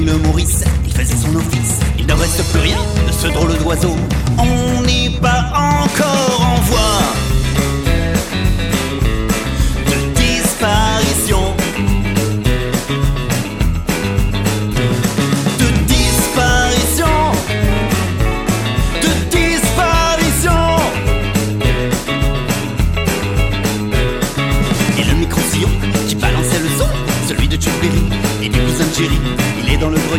un duo de musiciens-chanteurs pour le jeune public